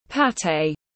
Pa tê tiếng anh gọi là pâté, phiên âm tiếng anh đọc là /ˈpæt.eɪ/